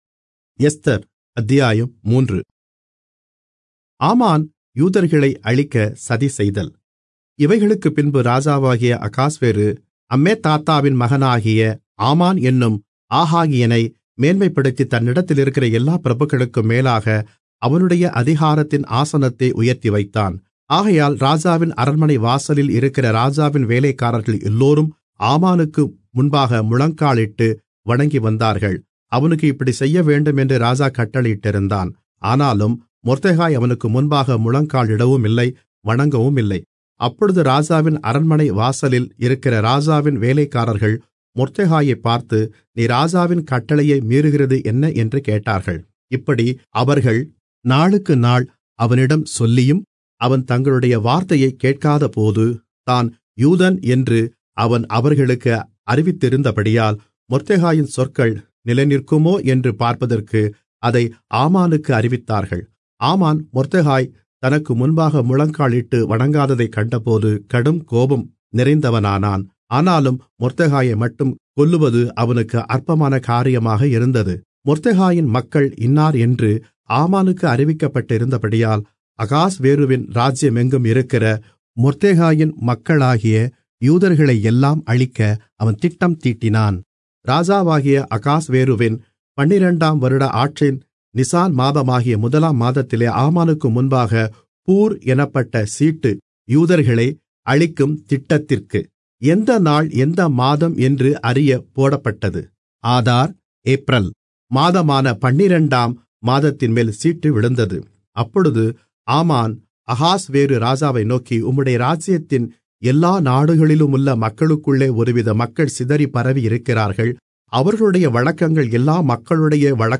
Tamil Audio Bible - Esther 4 in Irvta bible version